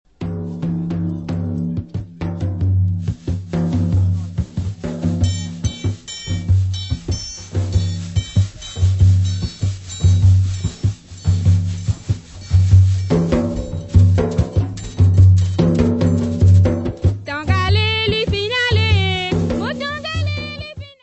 : stereo; 12 cm
Séga ravanne mauricien / groupe Zenfan Ti Rivière.